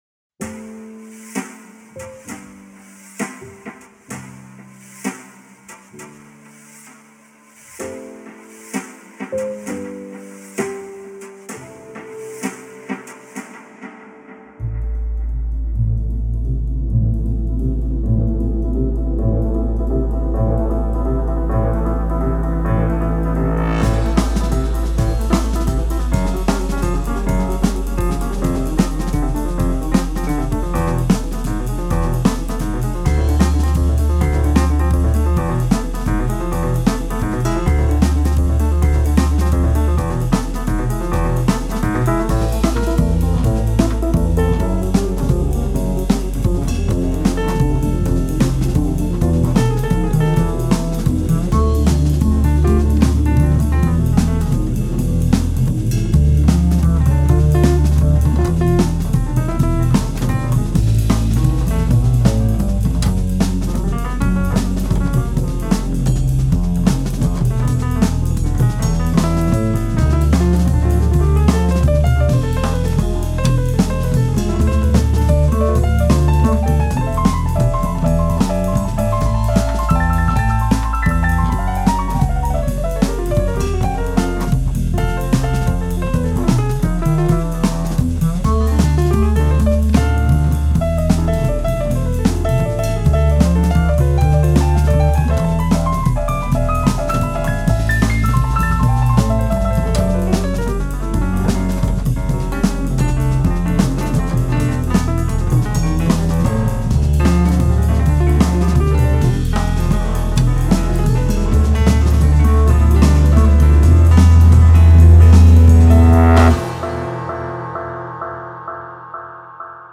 Жанр: Alternativa.